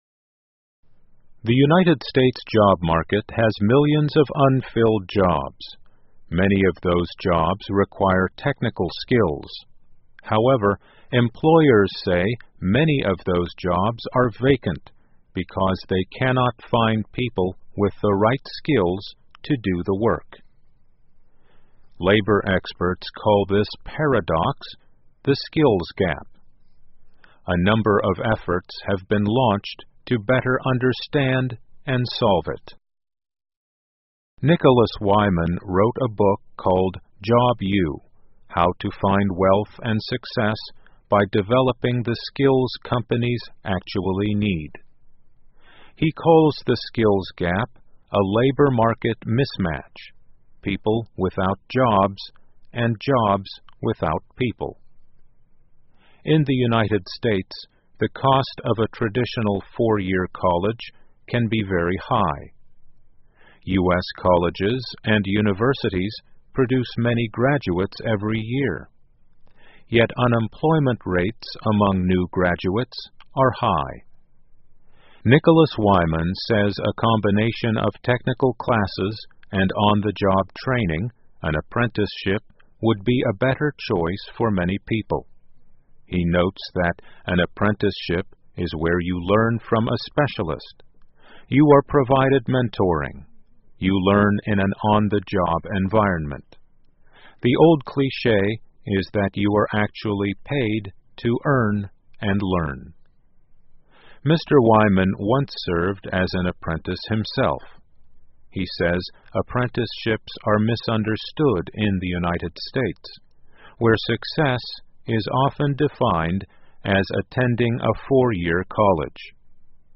在线英语听力室美国人才市场上有“能力差距”一说吗?的听力文件下载,2015年慢速英语(七)月-在线英语听力室